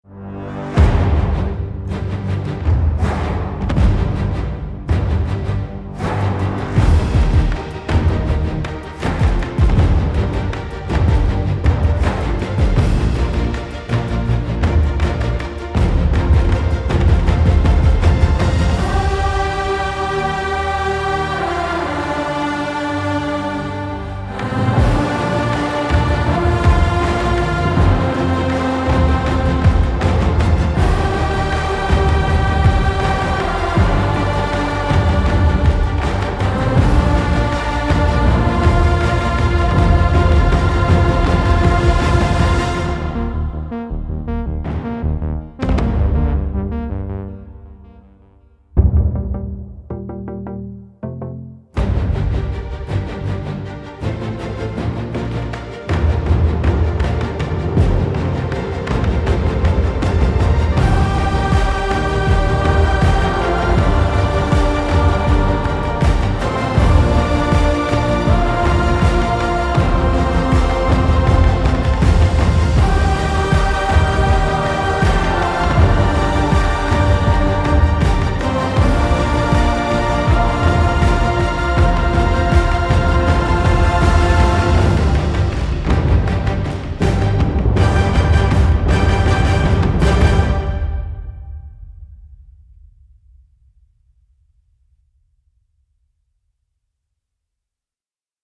• Качество: 320, Stereo
громкие
мощные
без слов
инструментальные
торжественные